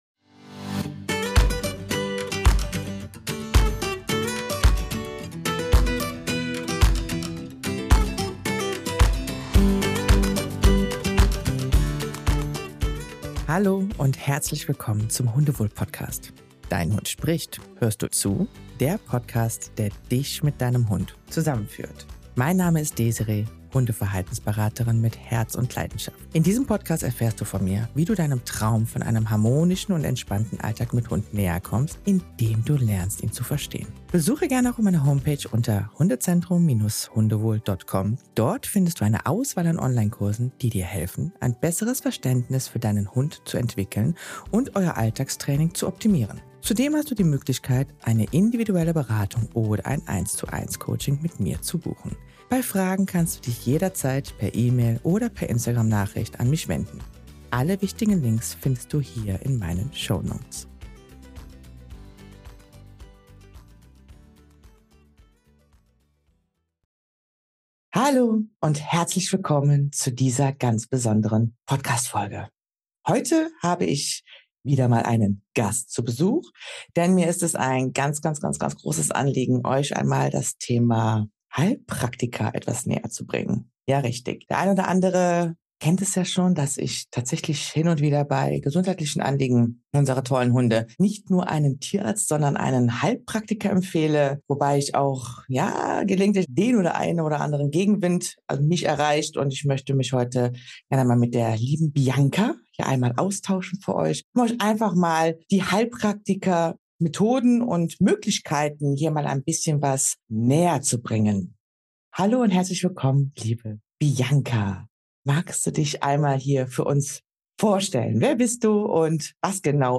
Interview ~ Dein Hund spricht, hörst du zu?